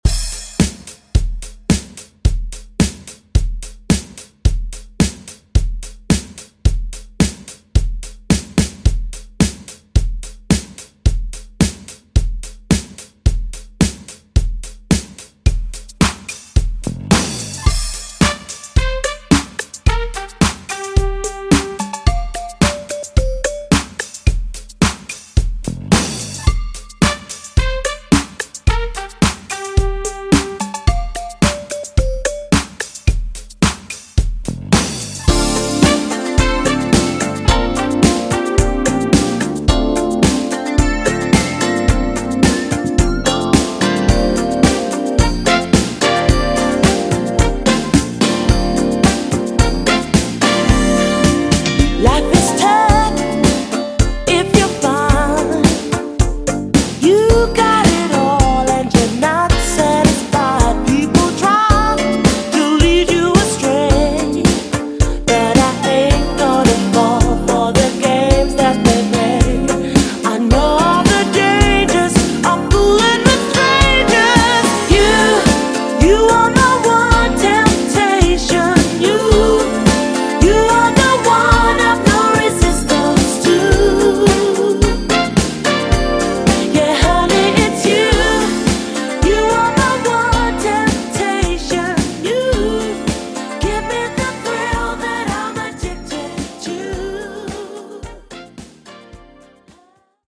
80s RnB ReDrum)Date Added